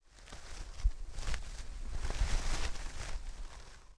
脚步走在丛林3zth070524.wav
通用动作/01人物/01移动状态/06落叶地面/脚步走在丛林3zth070524.wav
• 声道 單聲道 (1ch)